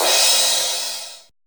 LINN CRASH.wav